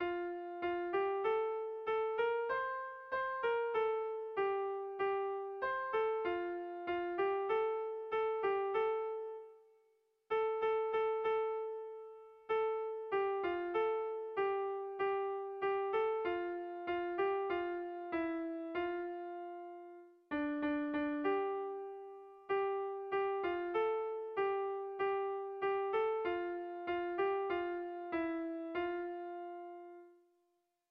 Erlijiozkoa
Lauko handia (hg) / Bi puntuko handia (ip)
AB